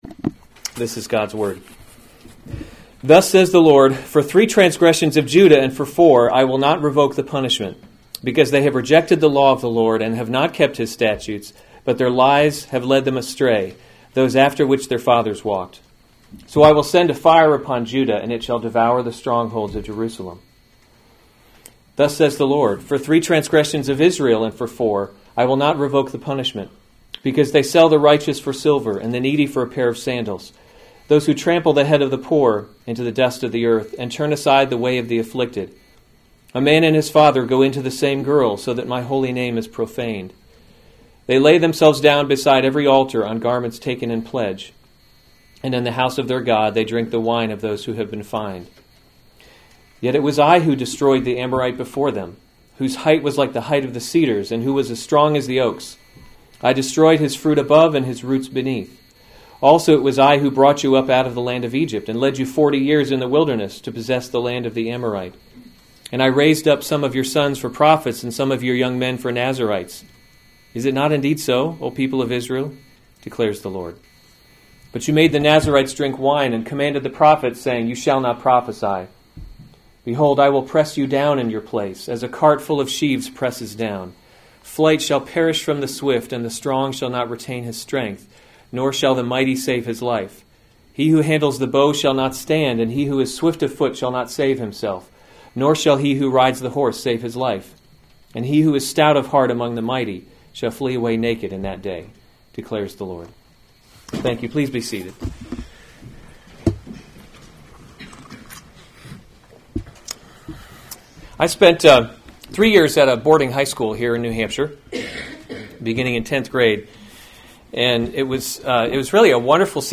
September 26, 2015 Amos: He’s Not a Tame Lion series Weekly Sunday Service Save/Download this sermon Amos 2:4-16 Other sermons from Amos Judgment on Judah 4 Thus says the Lord: “For […]